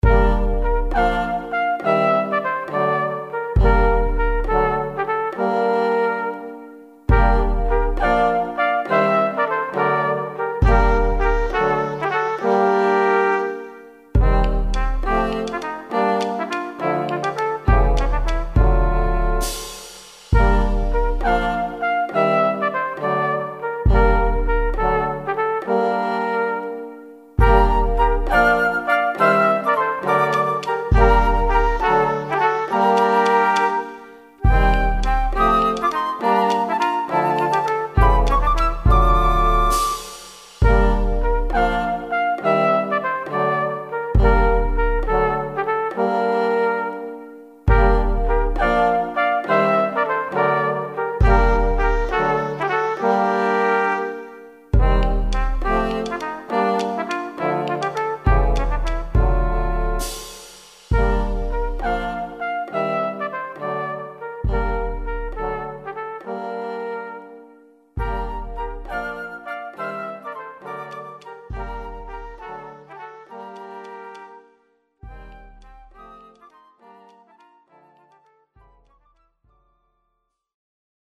Kinderhörspiel
Oboe, Oboe d'amore
Trompete, Flügelhorn
Posaune, Susafon
Schlagwerk
Tasteninstrumente, Schlagwerk, Maultrommel